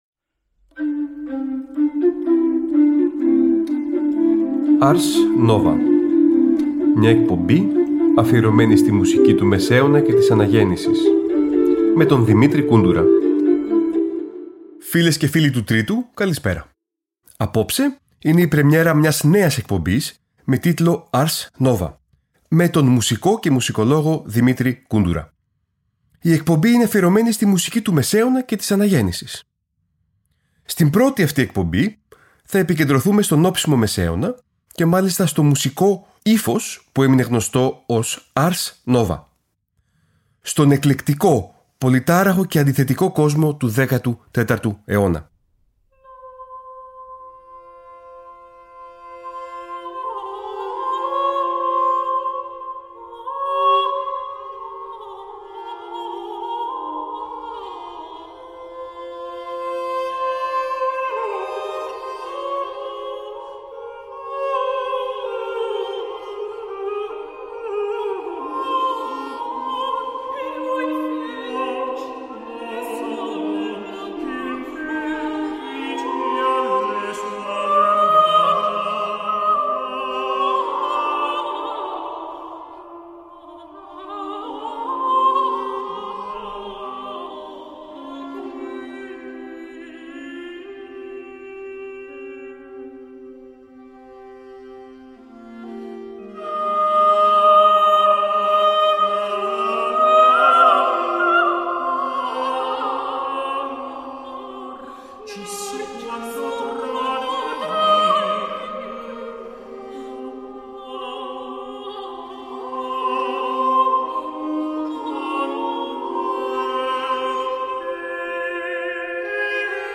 Πρεμιέρα για τη νέα ωριαία μουσική εκπομπή του Τρίτου Προγράμματος που θα μεταδίδεται από τις 12 Δεκεμβρίου 2023 και κάθε Τρίτη στις 19:00.